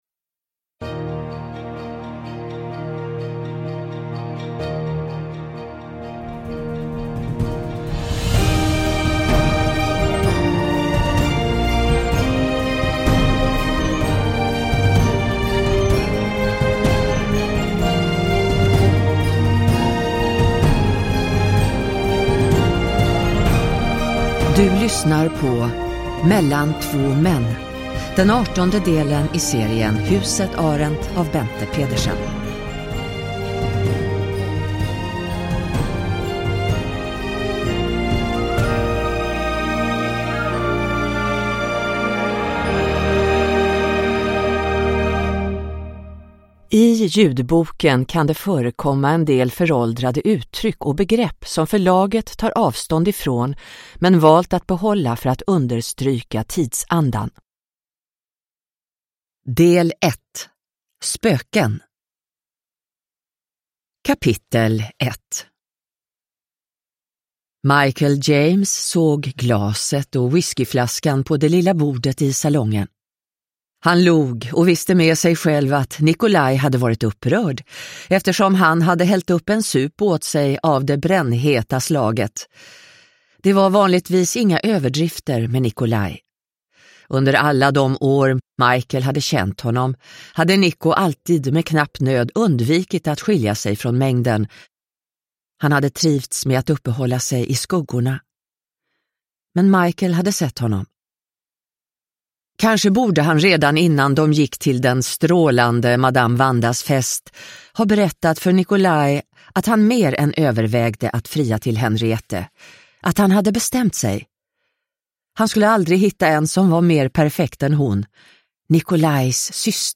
Mellan två män – Ljudbok – Laddas ner